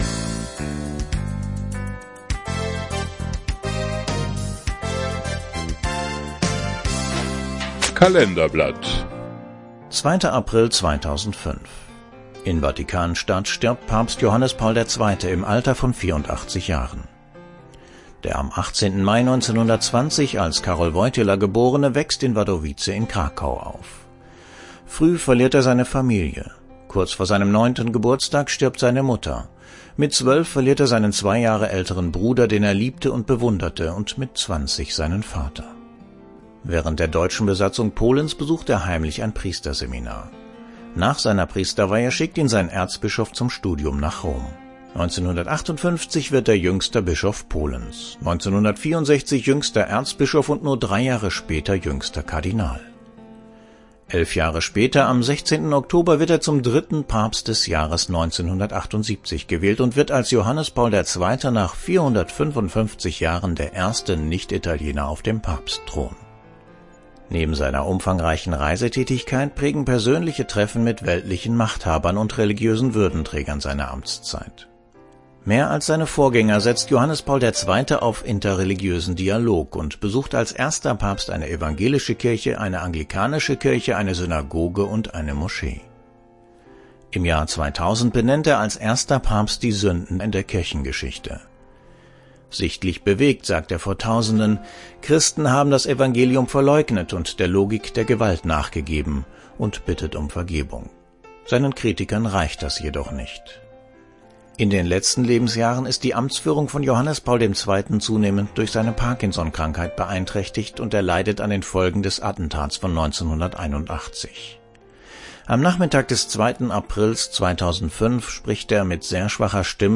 Sprecher: